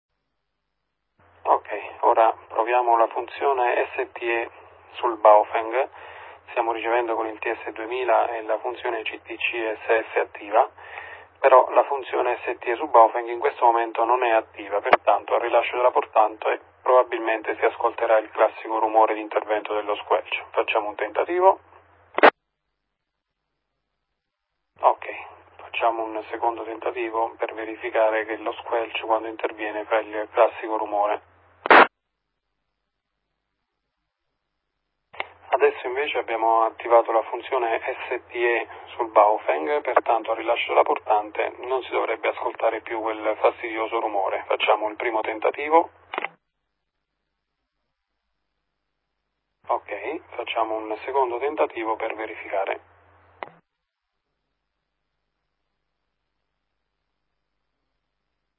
Ecco un breve file audio con le prove effettuate da me sulla funzione STE del Baofeng.